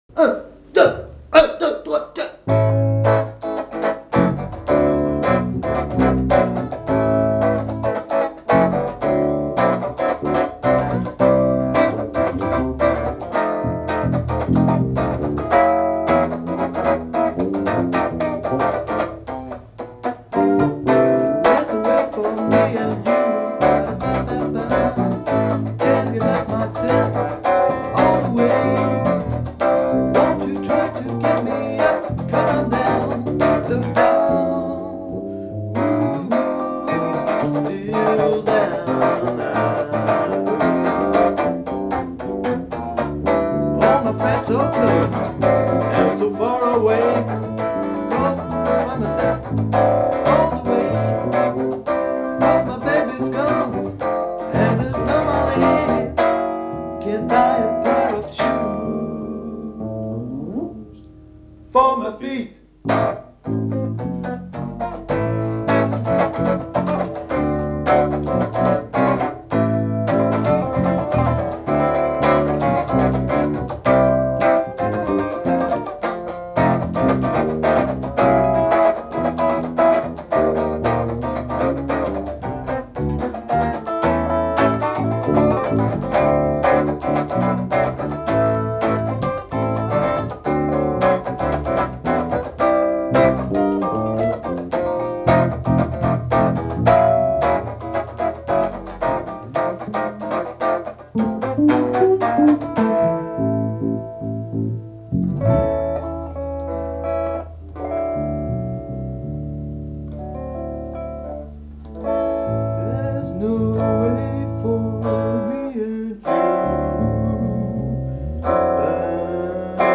Blues des débuts. 1994